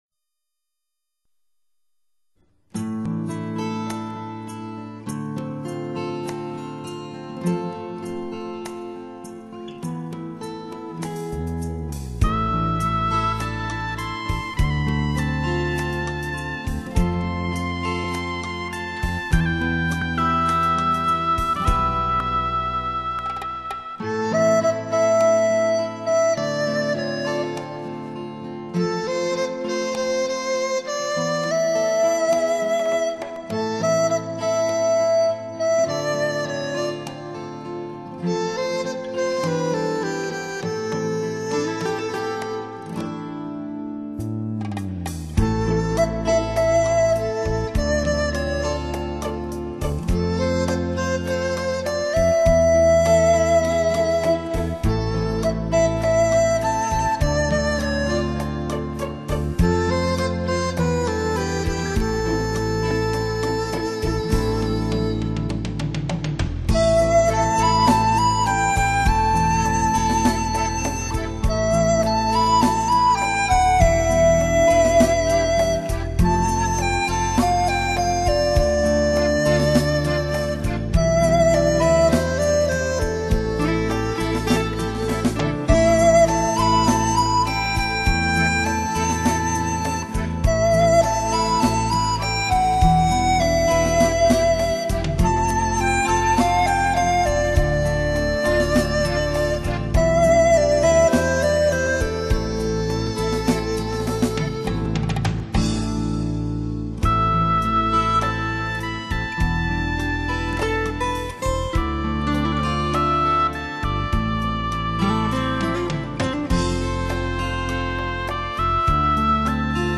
笛，是民间流传最广的吹管乐器，常见的 有梆笛及曲笛两种，梆笛音色高亢、清脆，曲 音色较淳厚、园润。